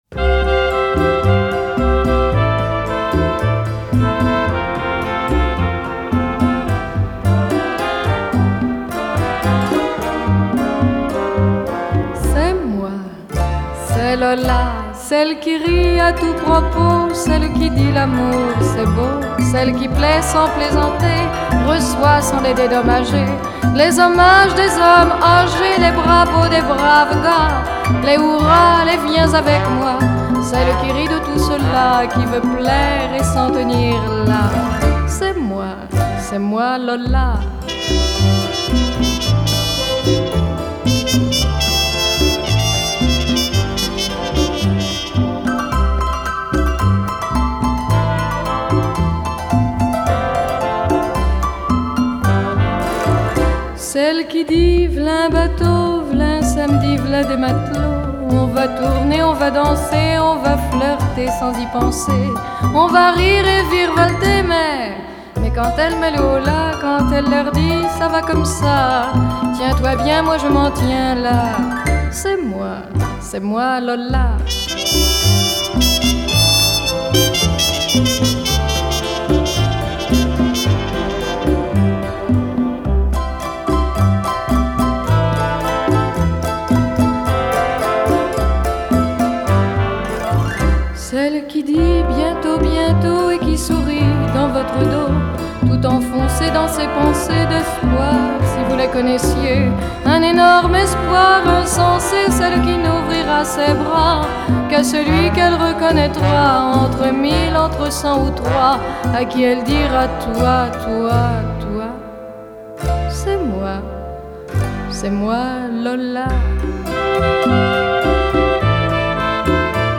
Жанр: Bossa Nova.